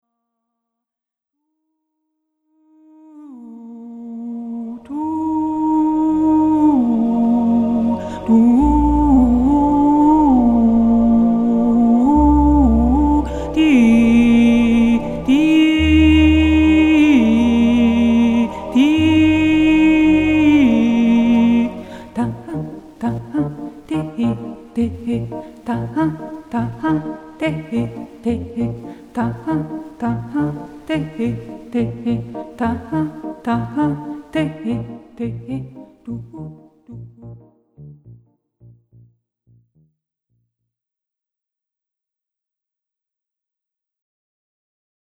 Momentum-Aufnahmen